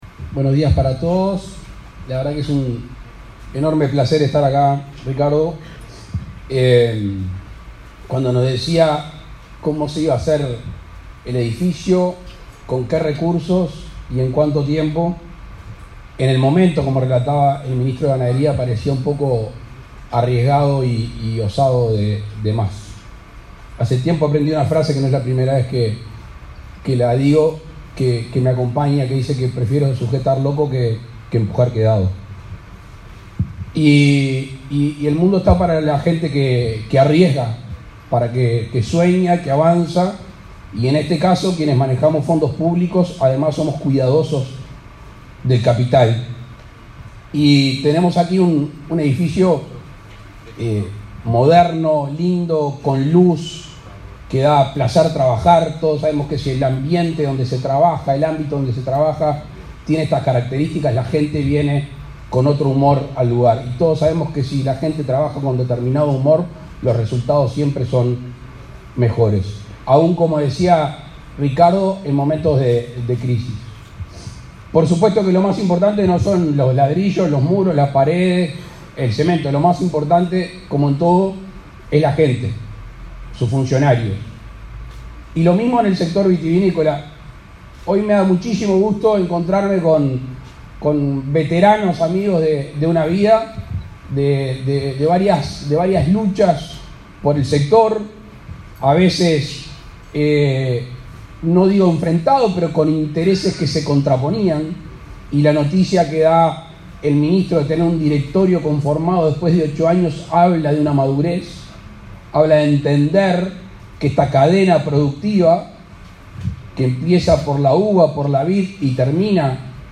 Palabras del presidente Luis Lacalle Pou
Palabras del presidente Luis Lacalle Pou 21/06/2022 Compartir Facebook X Copiar enlace WhatsApp LinkedIn El presidente Luis Lacalle Pou encabezó el acto de inauguración de la sede del Instituto Nacional de Vitivinicultura (Inavi), ubicada en la localidad de Las Piedras, Canelones.